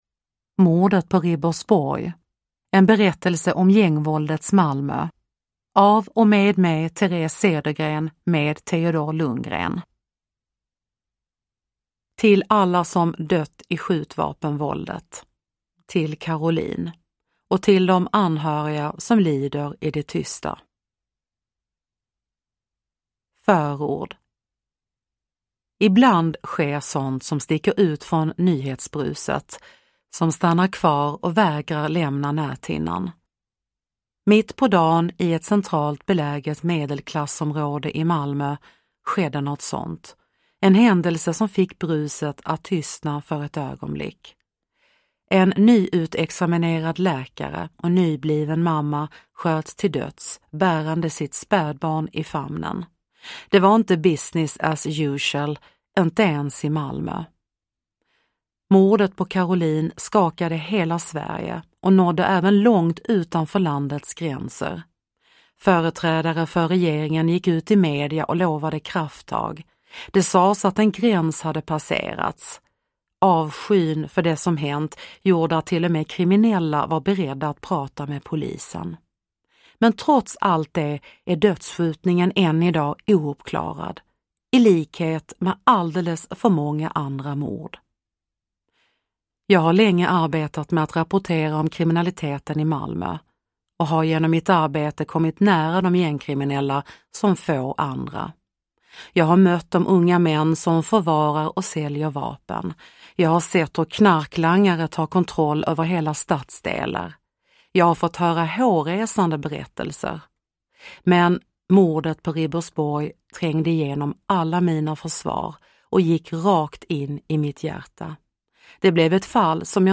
Mordet på Ribersborg : en berättelse från gängvåldets Malmö – Ljudbok – Laddas ner